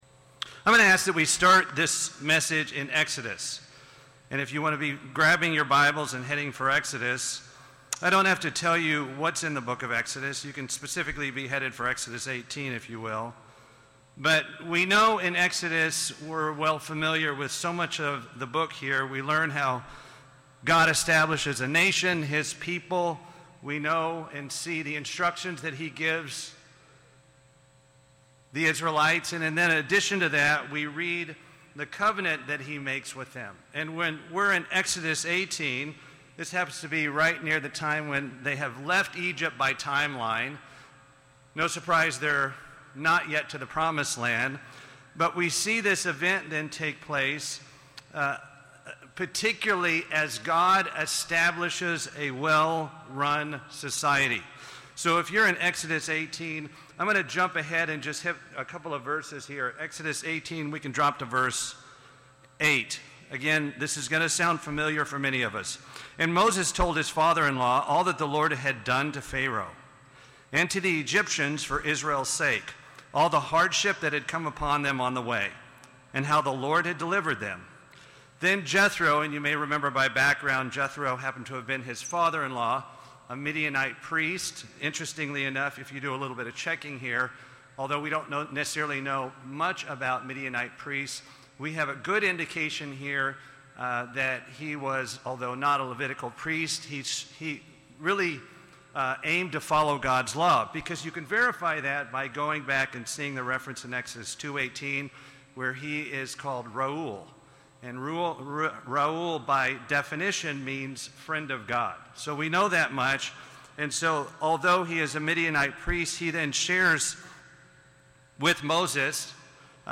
This sermon was given at the Daytona Beach, Florida 2021 Feast site.